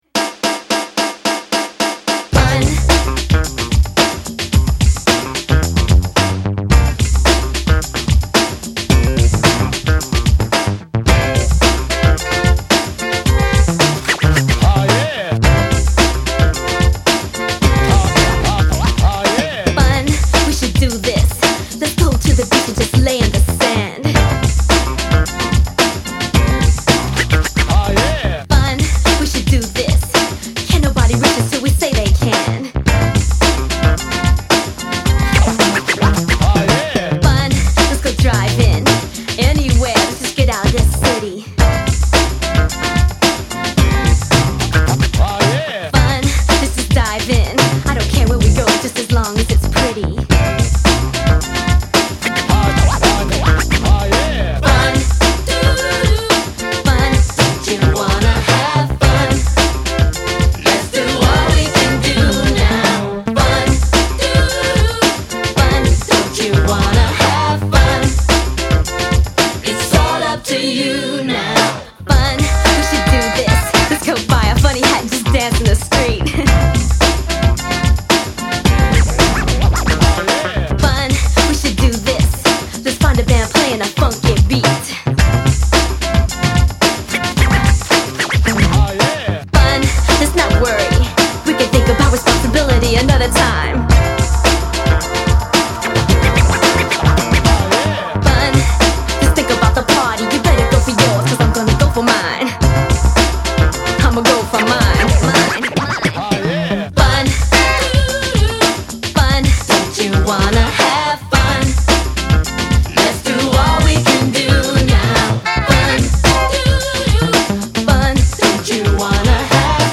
pop R&B